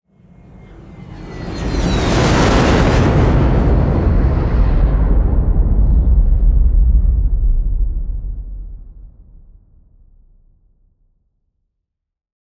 SPACESHIP Fly By Big Long Rumble 01.wav